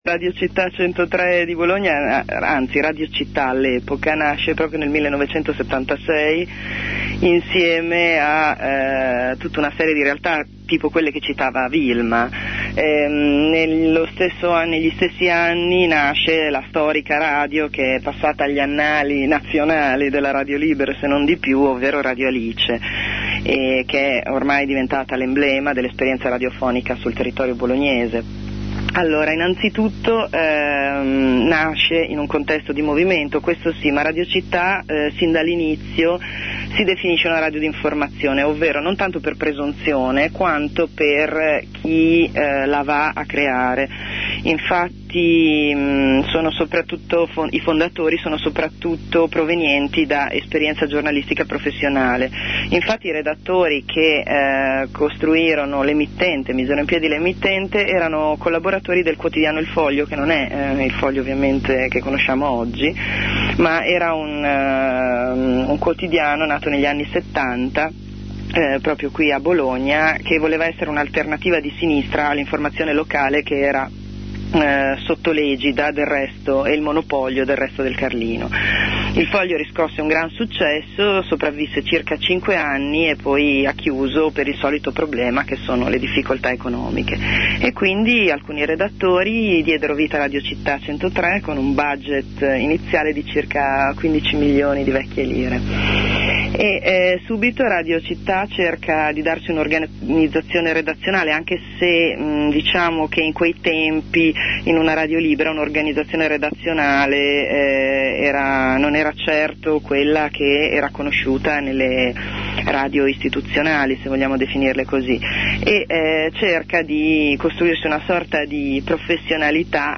intervista a Radio Citta'103